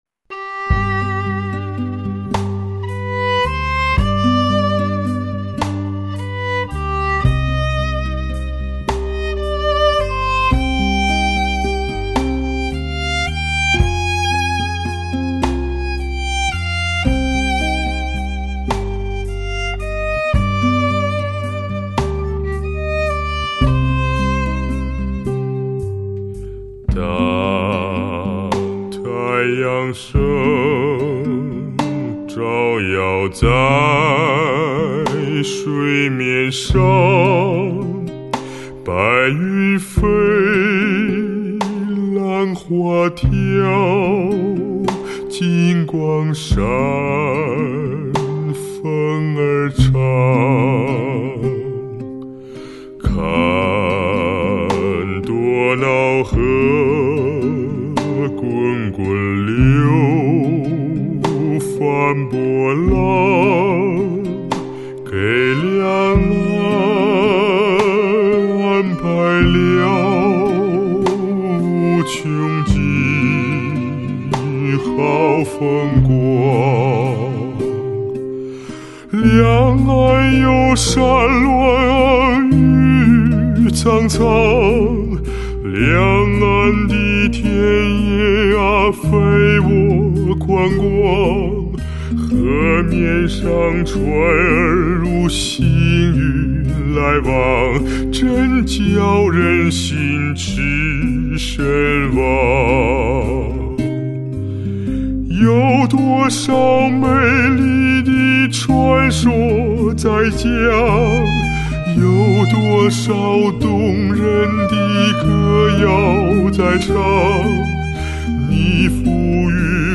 的运用，音质之甘美饱满令人惊艳赞叹，他的音域宽广，声音始终保持在一个歌唱状态下，